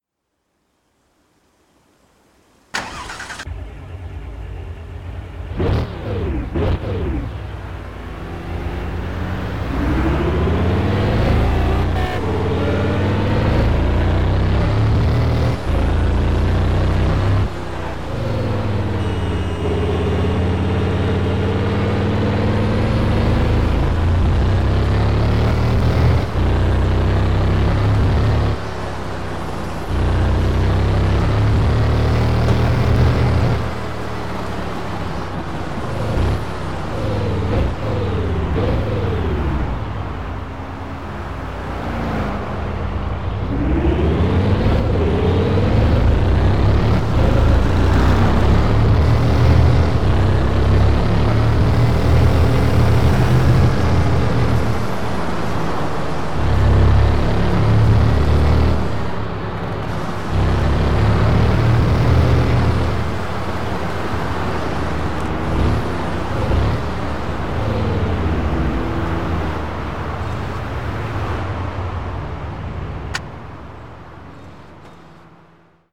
- Bugatti Veyron 16.4